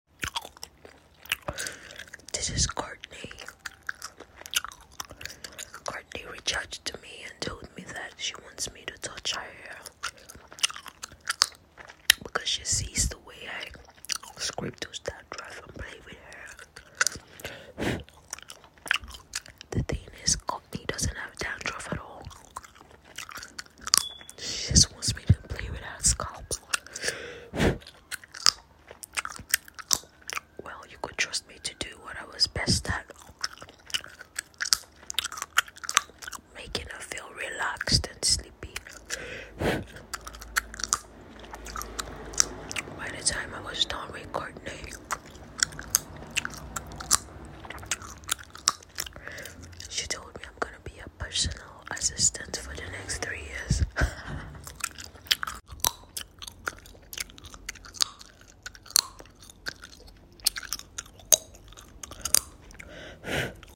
Quick hair play, parting and sound effects free download